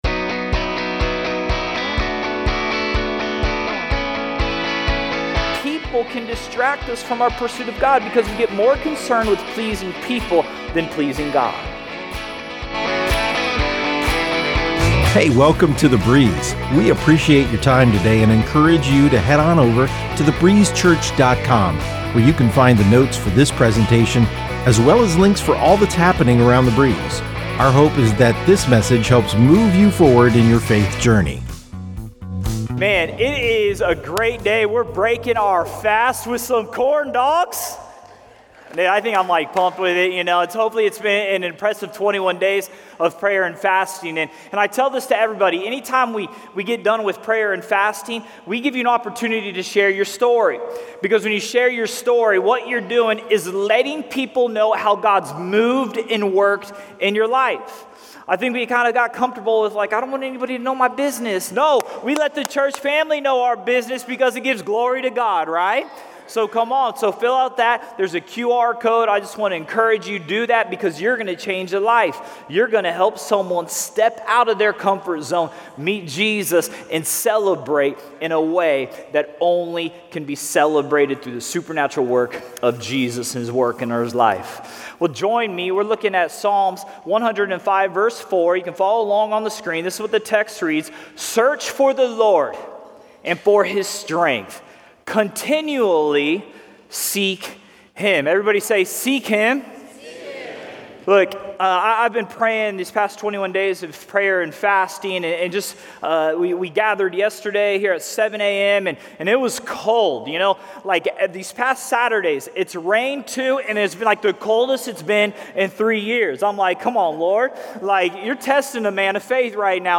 Messages from The Breeze Church, North Fort Myers, FL